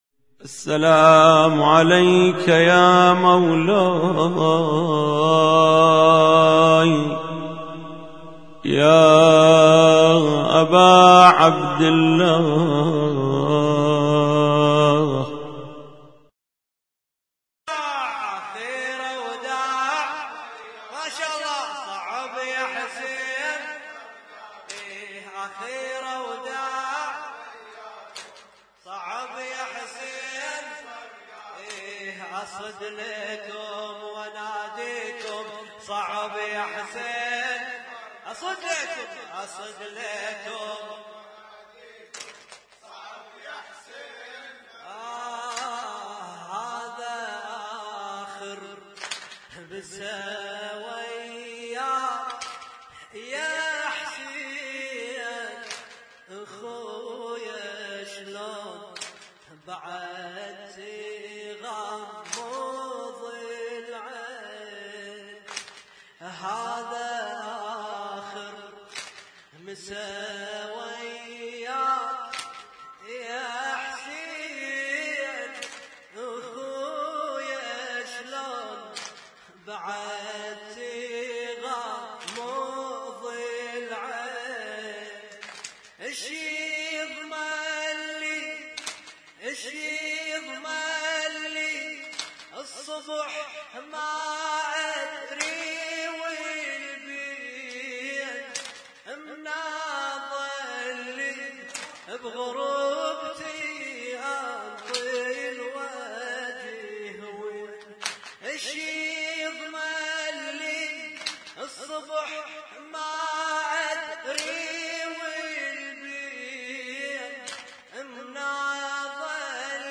Husainyt Alnoor Rumaithiya Kuwait
لطم - ليلة 10 محرم 1436